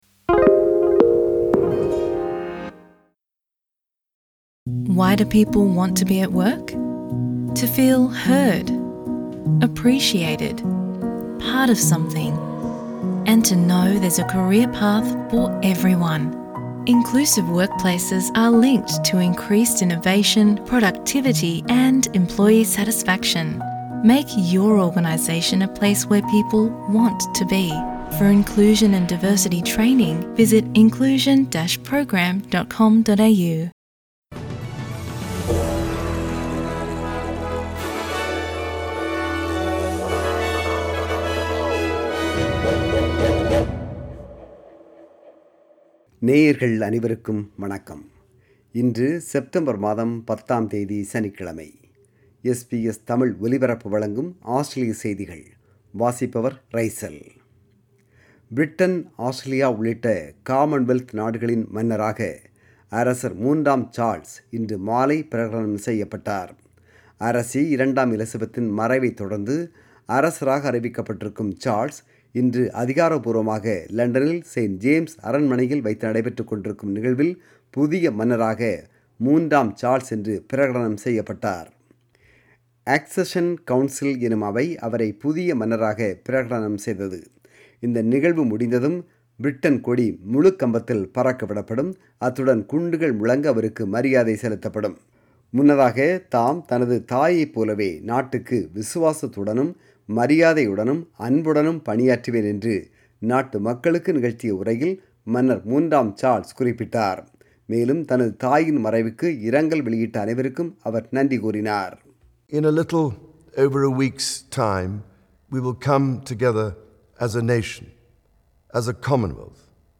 Australian News: 3 September 2022 – Saturday